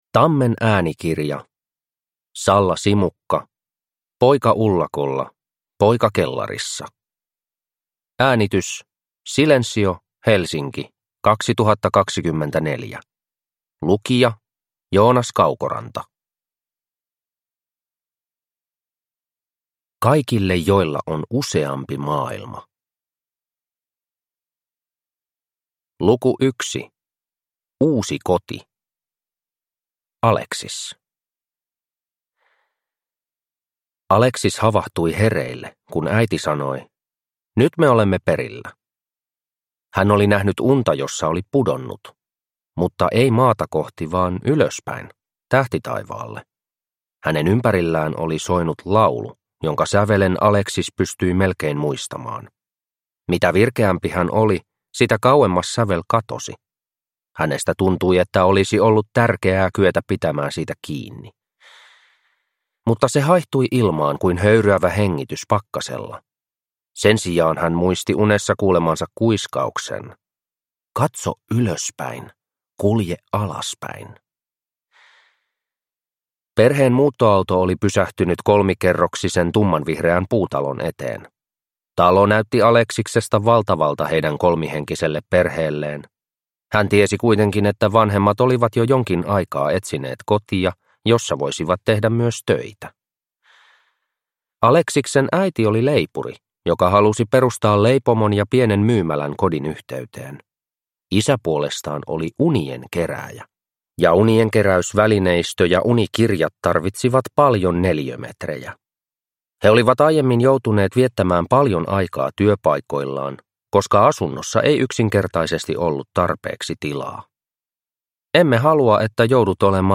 Poika ullakolla, poika kellarissa – Ljudbok